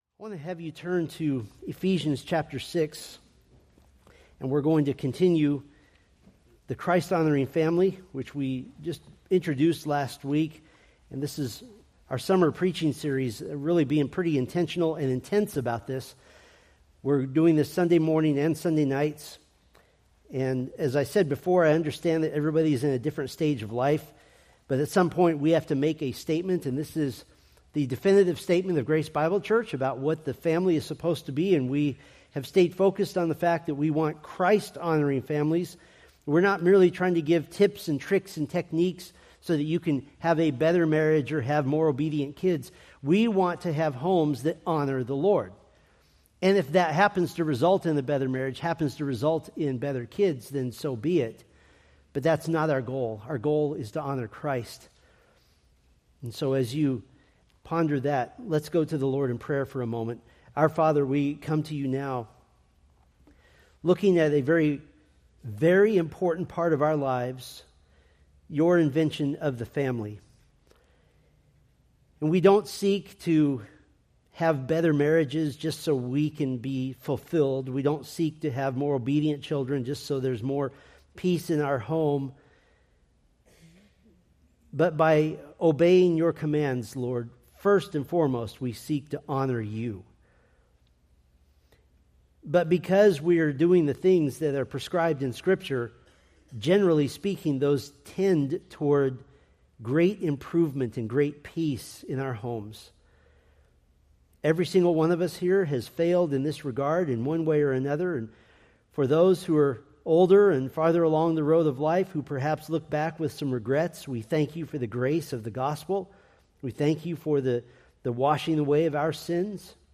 Preached July 6, 2025 from Selected Scriptures